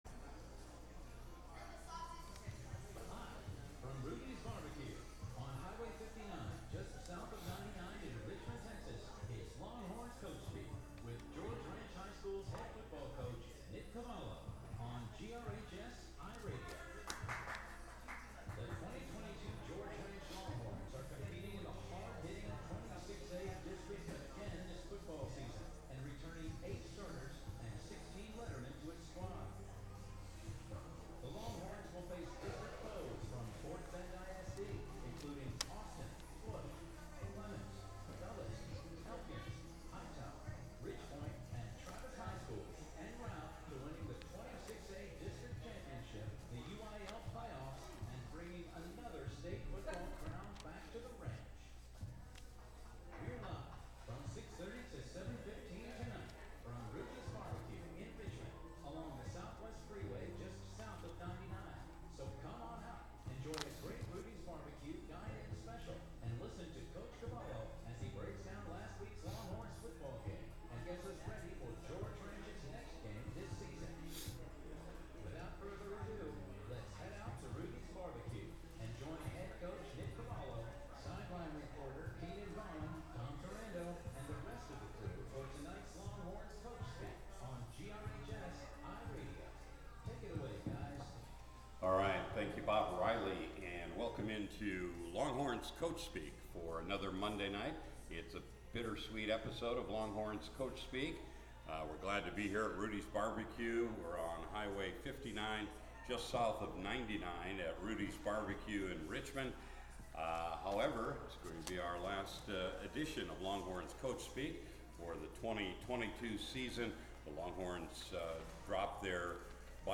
"Longhorns Coach Speak" from Rudy's Bar B Q in Richmond, Texas